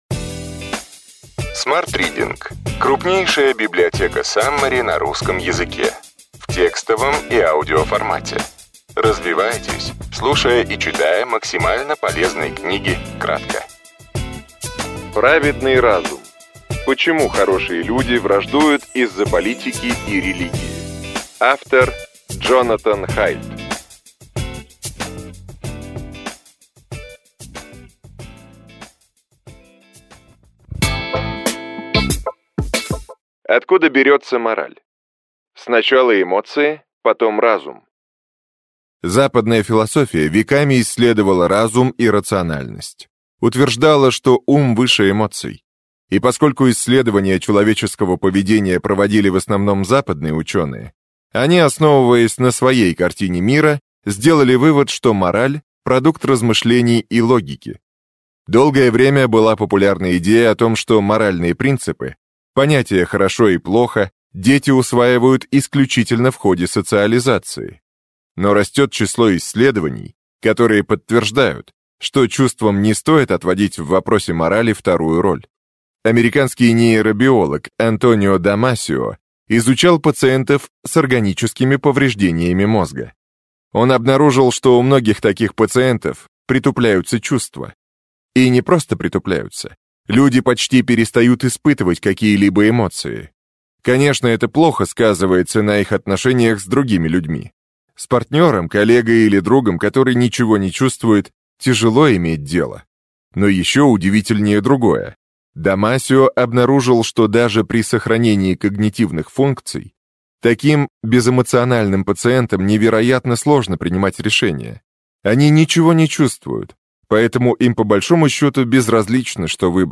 Аудиокнига Праведный разум. Почему хорошие люди враждуют из-за политики и религии.